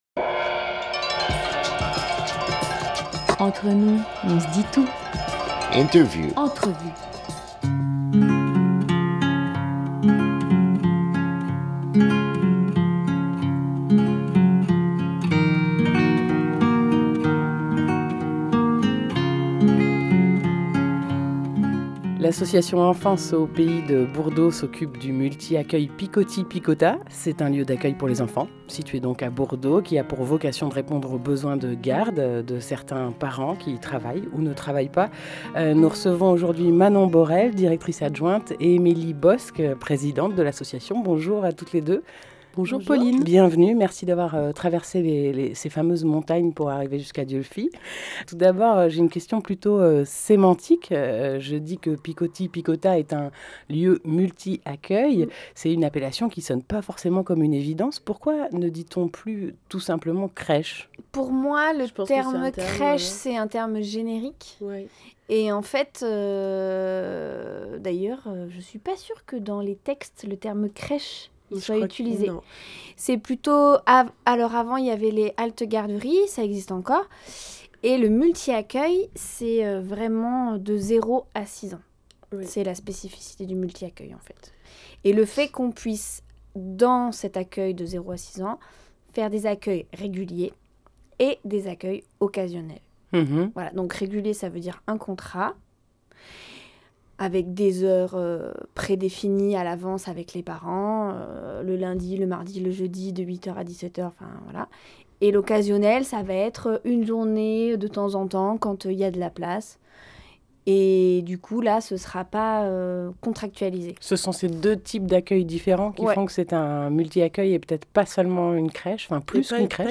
7 février 2019 17:57 | Interview